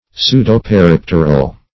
Pseudo-peripteral \Pseu`do-pe*rip"ter*al\, a. [Pseudo- +